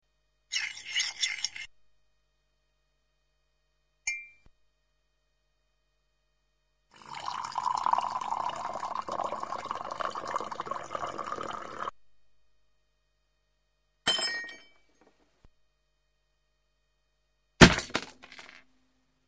1. Cup put down
2. Spoon stirring (the rhythmical nature of this sample creates the tempo of the piece)
3. Spoon hitting rim
4. Water poured into cup
5. Cup smashed against wall
6. Foot crushing broken pieces
rawteacup.mp3